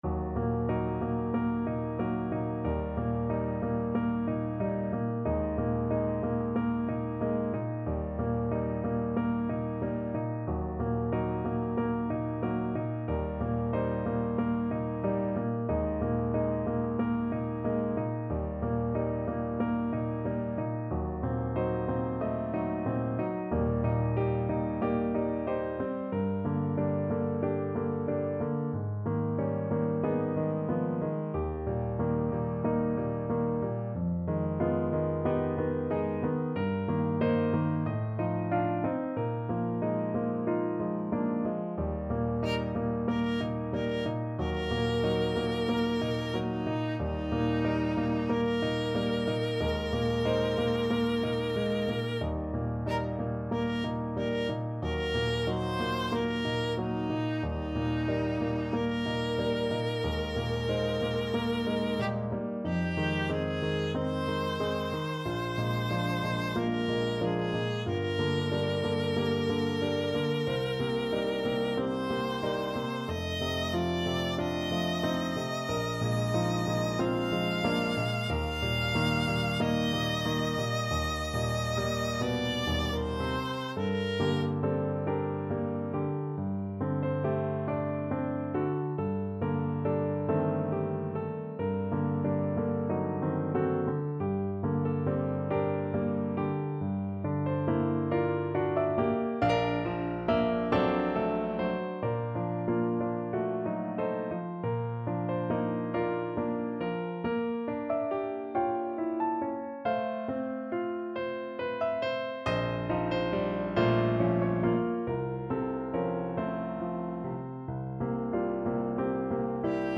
Classical Rachmaninoff, Sergei Cello Sonata, Op. 19, Slow Movement Viola version
Viola
Eb major (Sounding Pitch) (View more Eb major Music for Viola )
4/4 (View more 4/4 Music)
Andante (=46)
Classical (View more Classical Viola Music)
cello-sonata-op-19_VLA.mp3